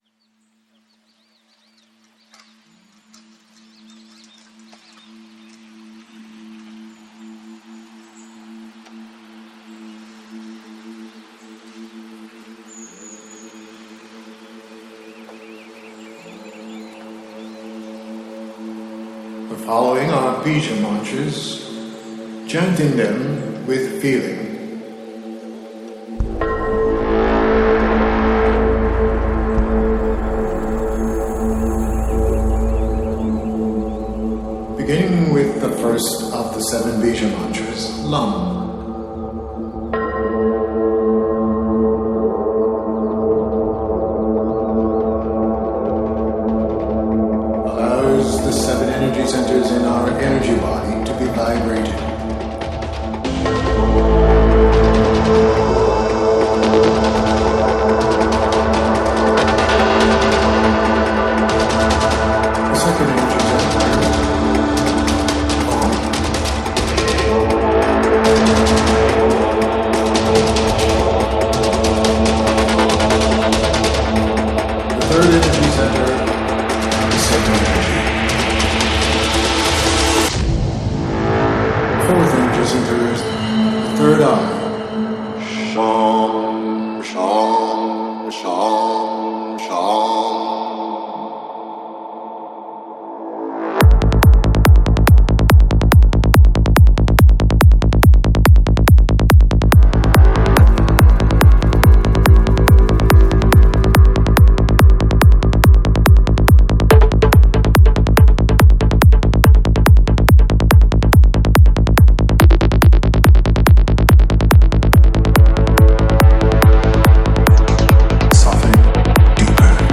Жанр: Psytrance